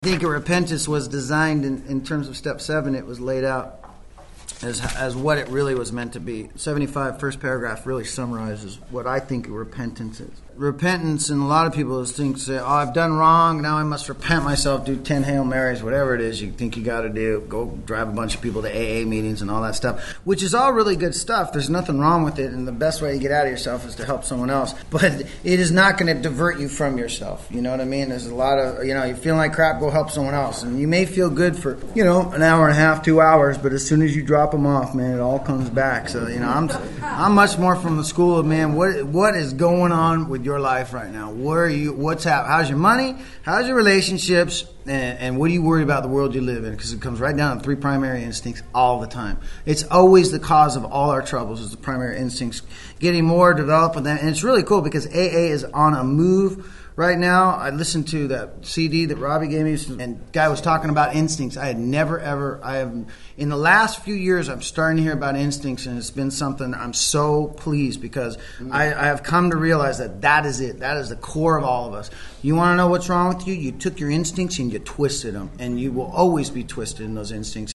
This audio archive is a compilation of many years of lecturing.
Within the lectures, you will hear people ask questions about why am I where I am, how can I get to a better place and what is blocking me.
This group discussion offers practical ways to pause before reacting, to turn harmful energy into positive action, and to approach daily life with a mindset of growth rather than perfection.